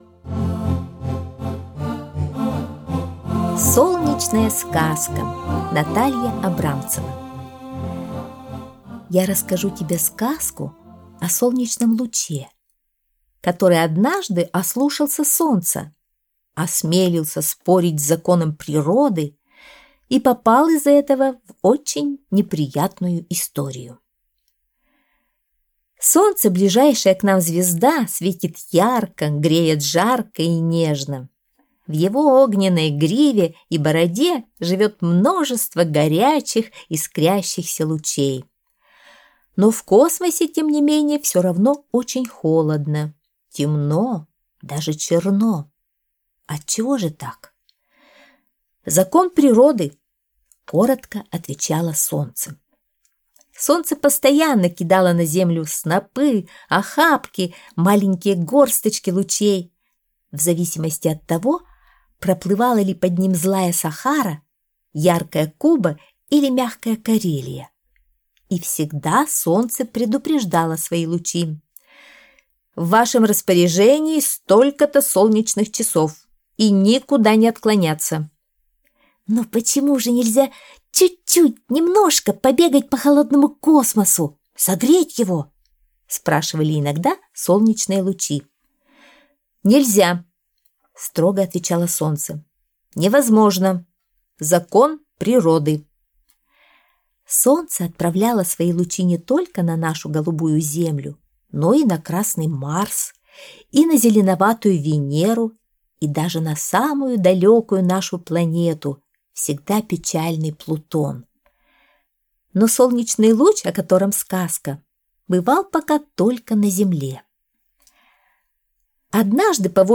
Солнечная сказка - аудиосказка Натальи Абрамцевой - слушать онлайн | Мишкины книжки
Солнечная сказка (аудиоверсия)